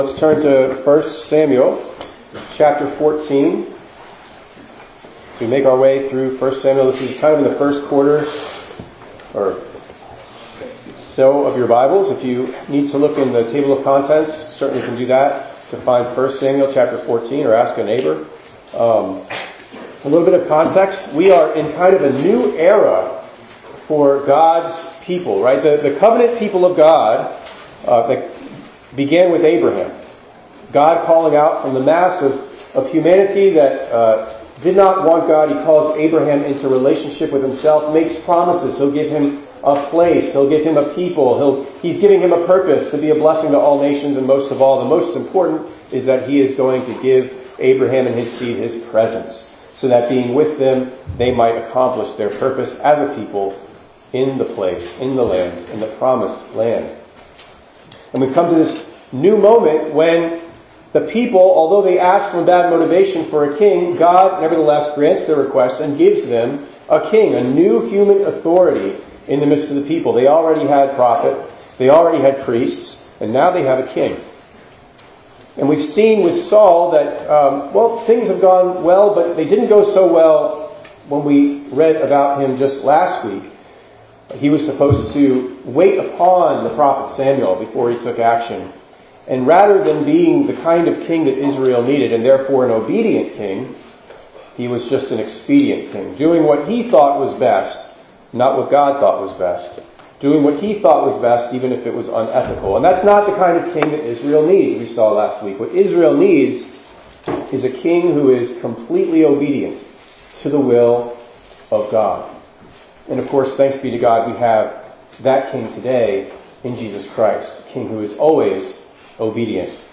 2_2_25_ENG_Sermon.mp3